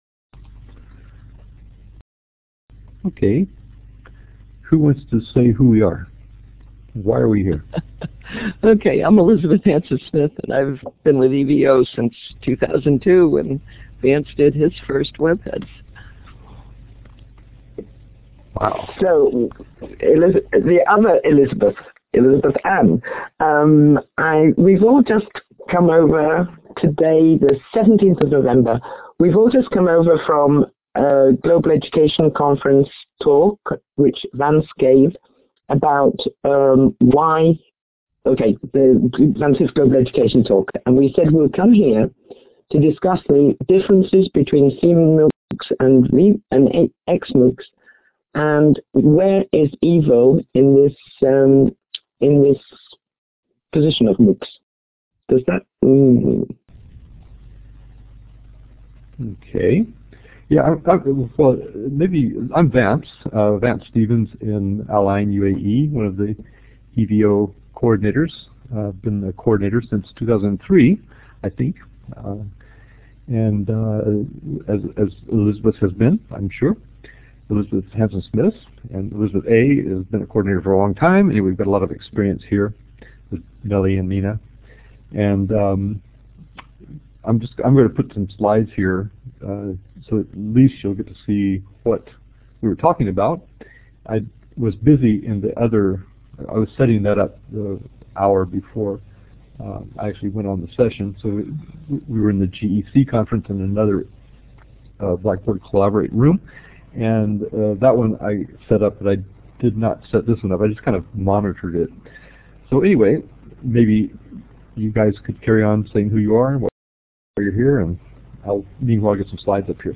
Her dissertation study is a case study research involving two semi-structured interviews, the first one between 60-90 minutes in length and the second one between 30-60 minutes in length, as well as observation of interactions online within the subject’s PLN.